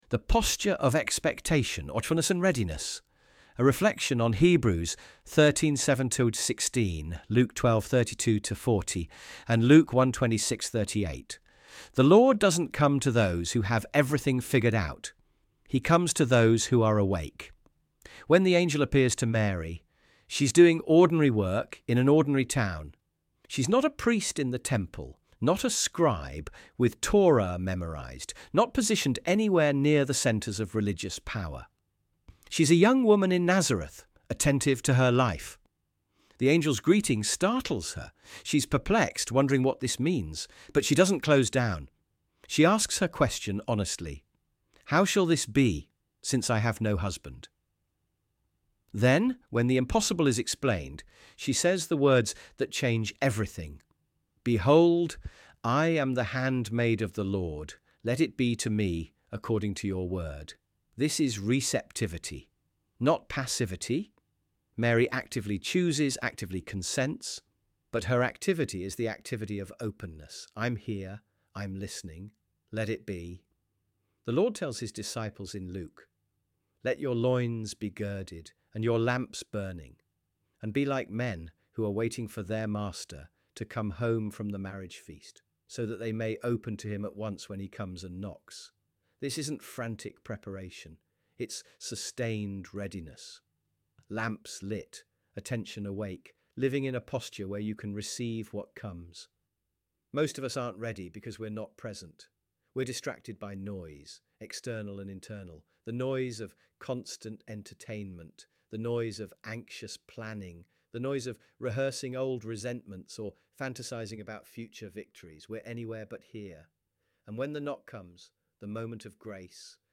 audio.texttospeech-13.mp3